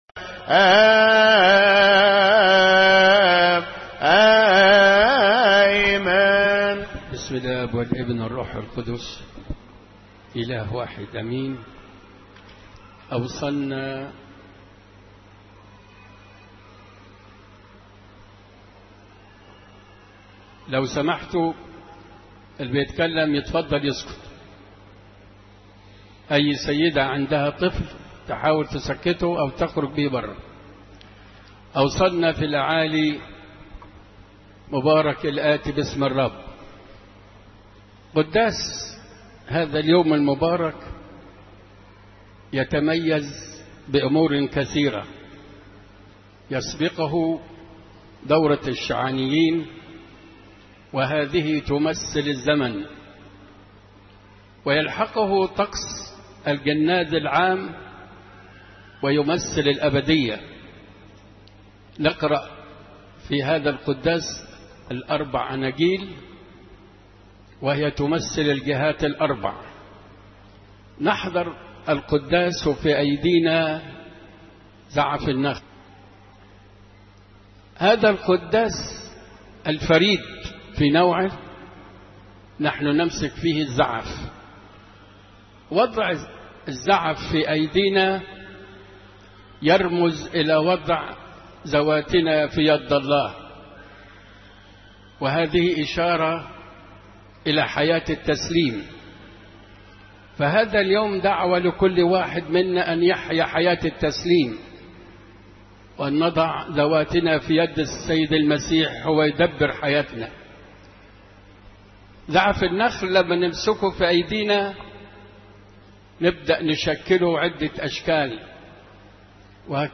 عظات اسبوع الآلام